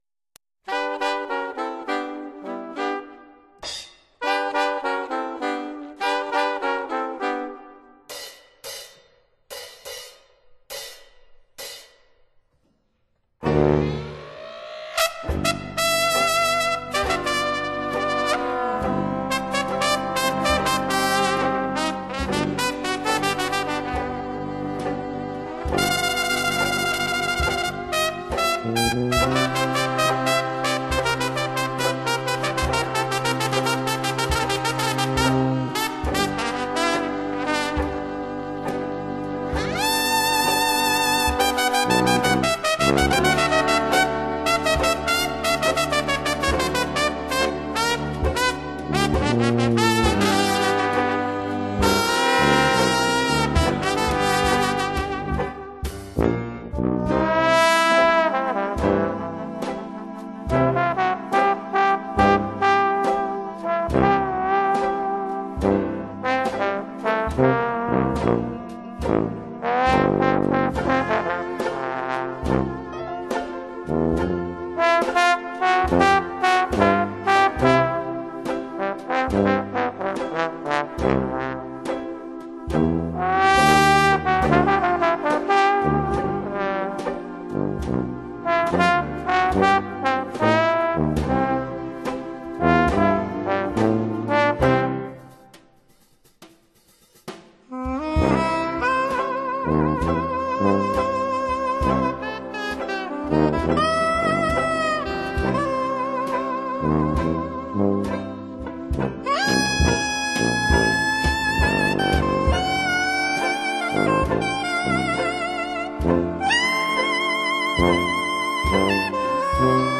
那是略帶哀愁失望，又有點慵懶無力的柔軟境界。
伴奏、錄音、製作都拋在腦後，只剩下那支在靜夜裡獨自鳴想著的、充滿惆悵的悲情小號。